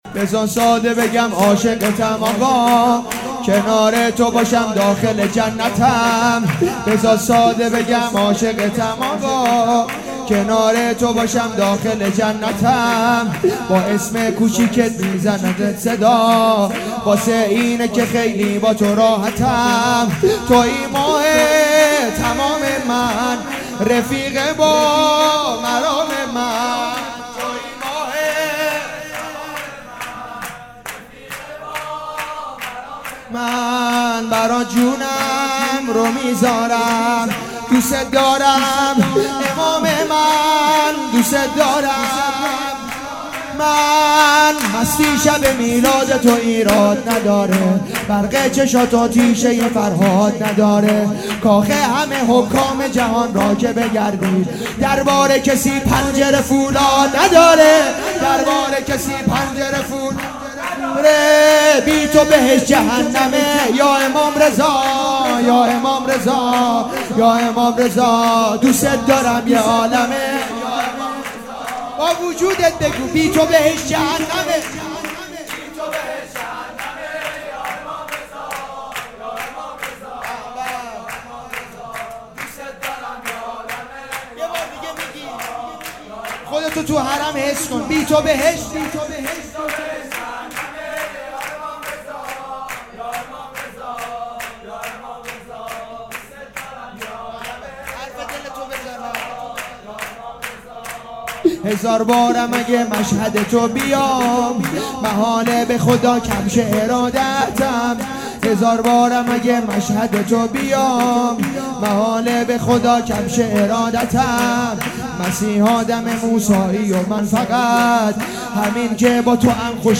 جشن ولادت امام جواد علیه السلام